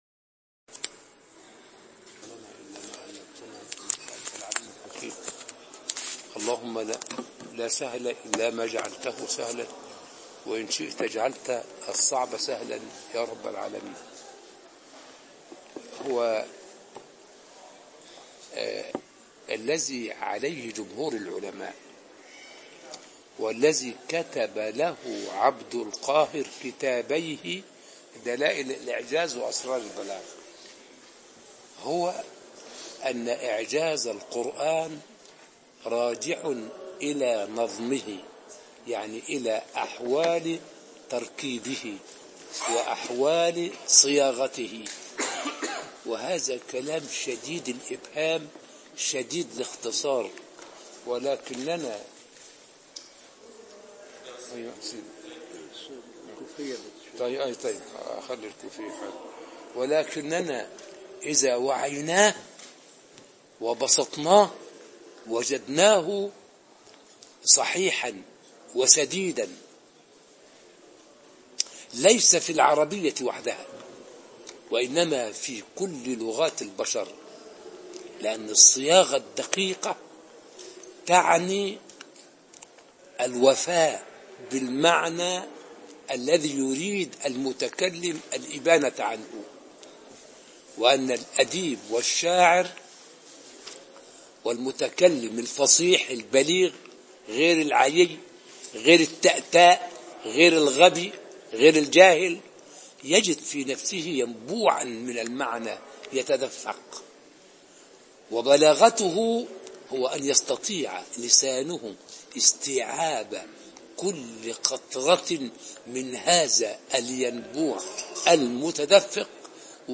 الدرس الرابع والثلاثون (شرح كتاب أسرار البلاغة)